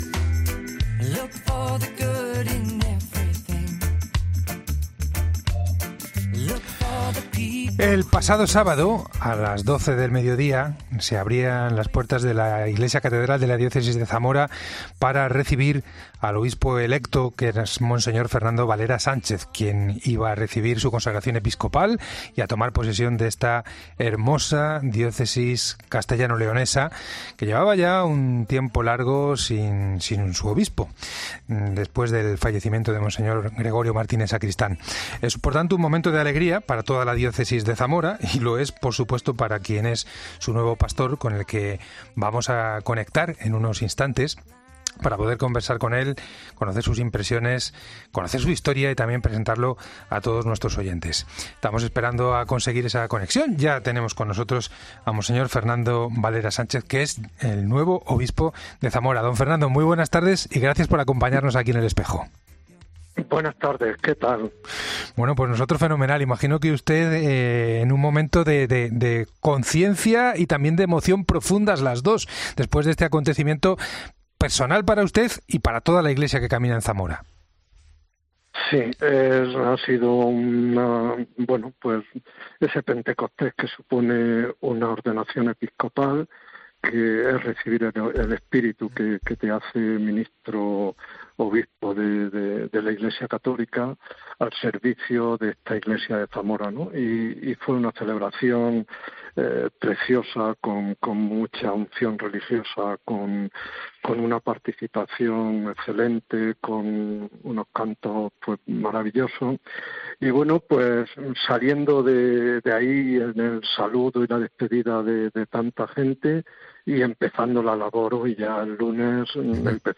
El nuevo obispo electo de Zamora analiza su nueva labor en el Espejo de la Cadena COPE